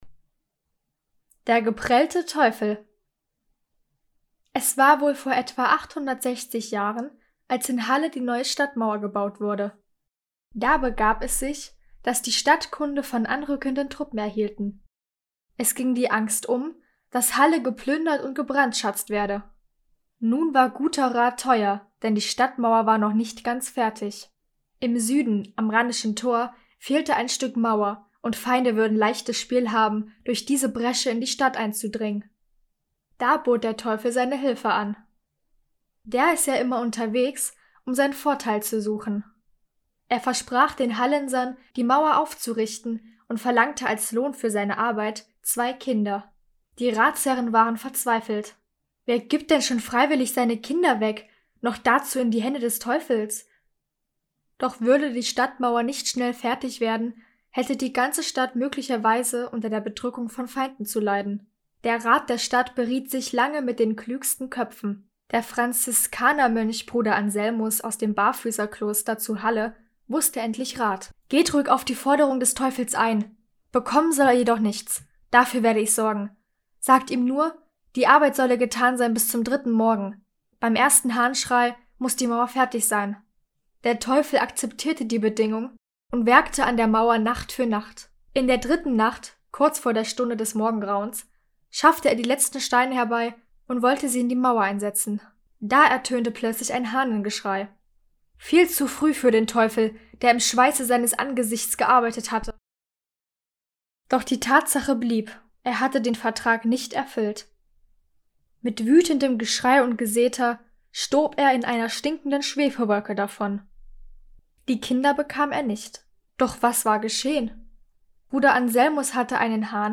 Sagen aus der Umgebung von Halle (Saale), gelesen von der